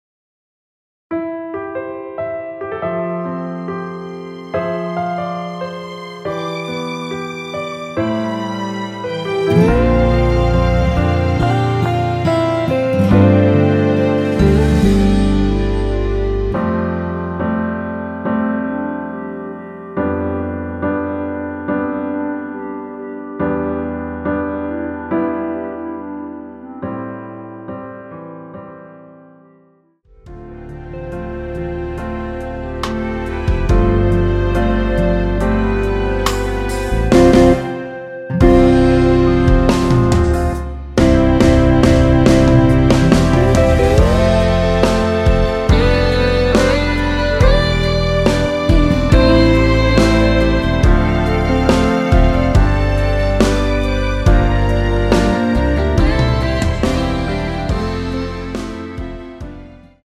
원키에서(-3)내린 멜로디 포함된 (1절앞+후렴)으로 진행되는 MR입니다.
앞부분30초, 뒷부분30초씩 편집해서 올려 드리고 있습니다.